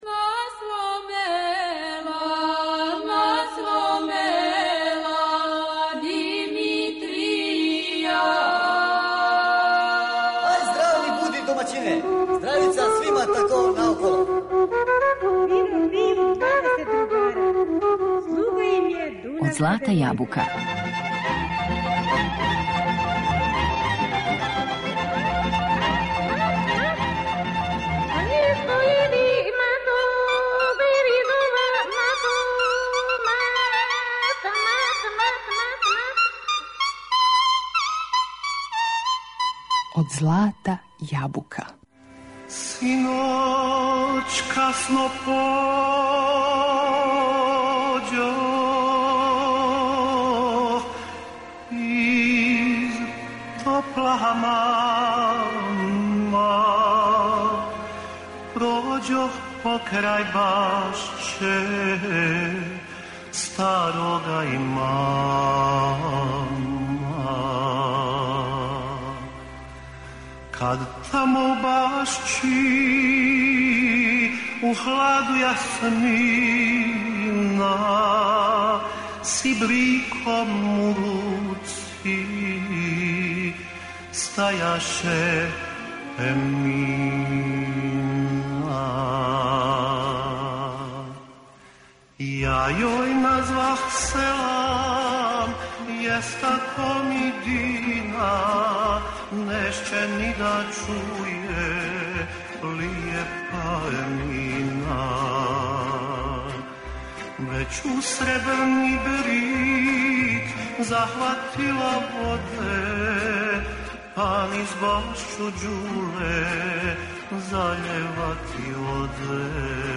Емитујемо један од последњих интервјуа овог уметника, из децембра 1984. године.